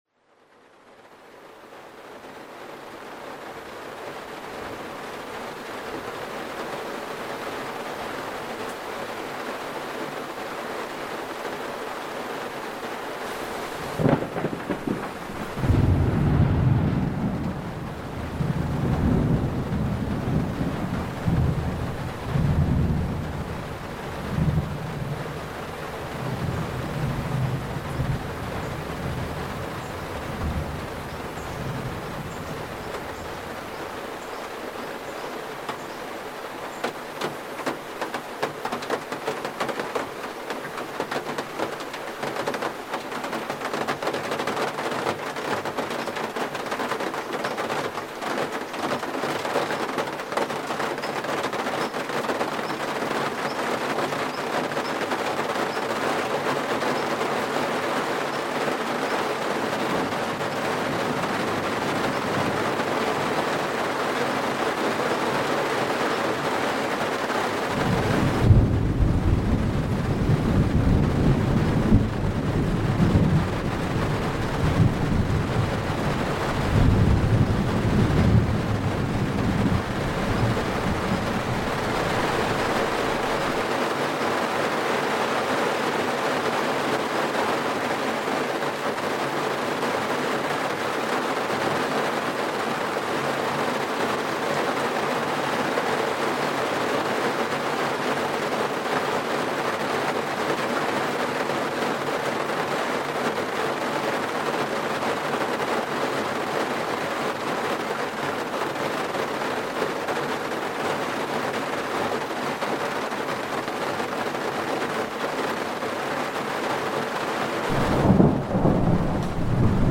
TIEFSCHLAF-PROGRAMMIERUNG: Nebelregen-Therapie mit Dach-Tropfen